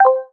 TouchpadSound_confirm.wav